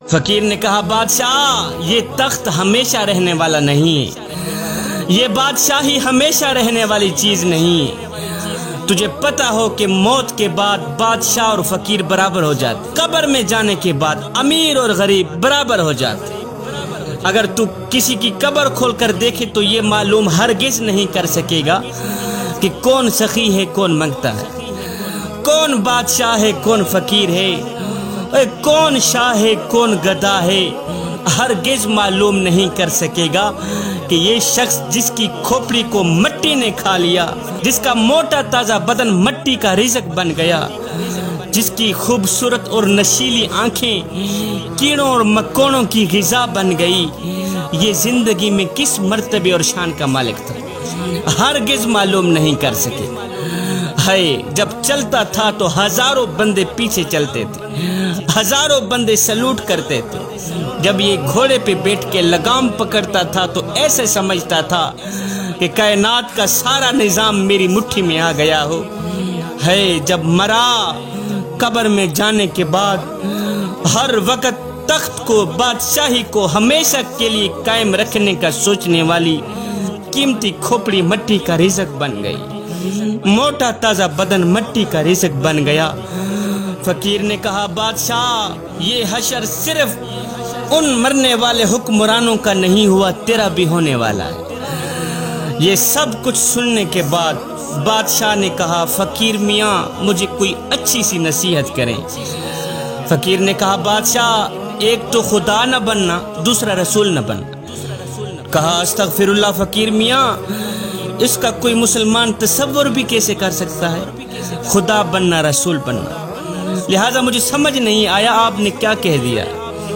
Faqeer Ki Nashiat MP3 Bayan Download